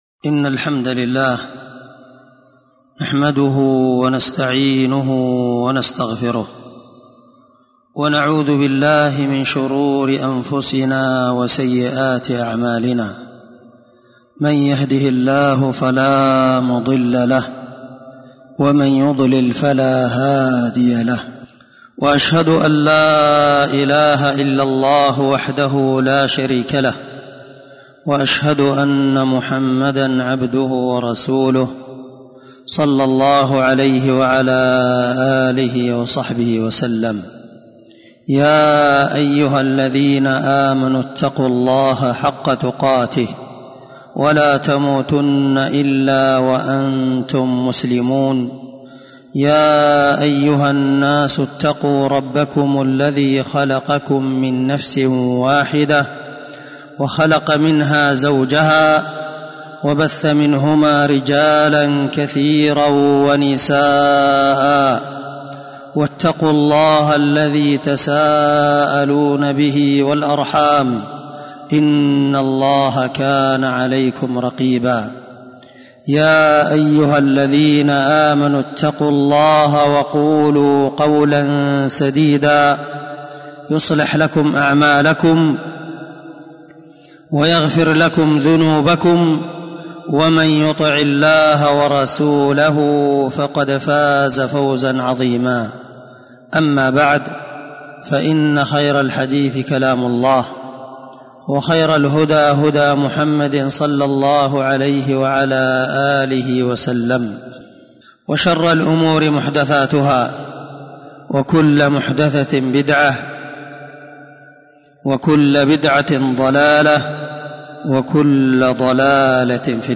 سلسلة خطب الجمعة